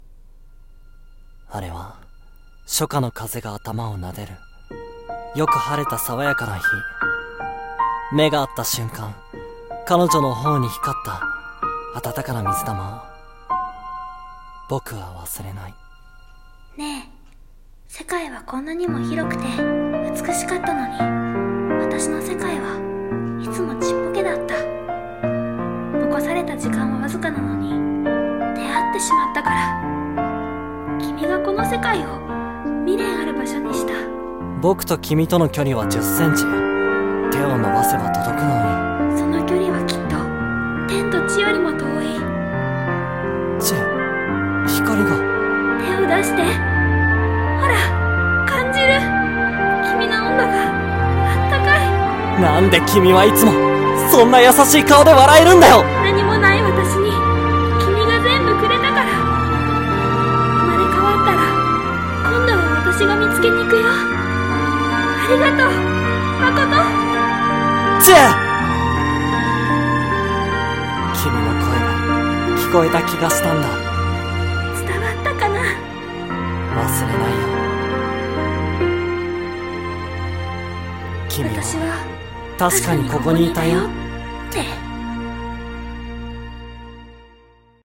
【映画予告風声劇】I'm Here